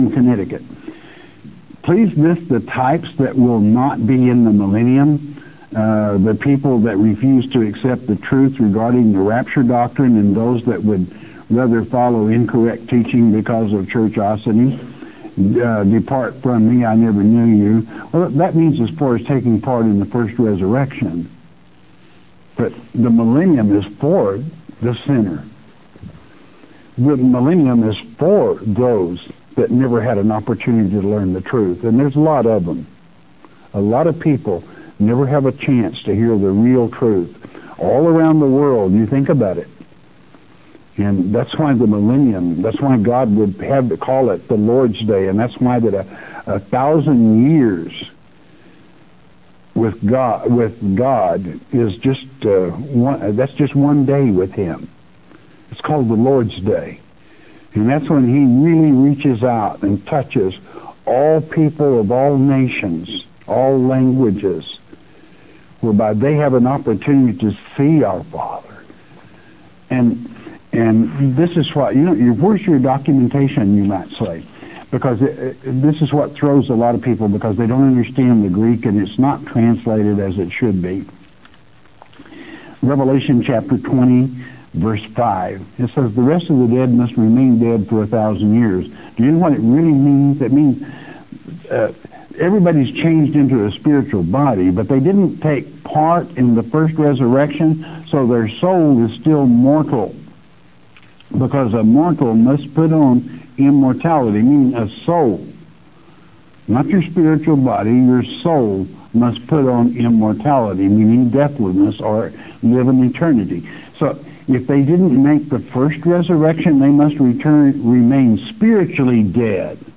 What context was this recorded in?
Click for 2˝ minute Audio-Bite (SCN television broadcast Feb 17, 2006)